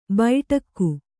♪ baiṭak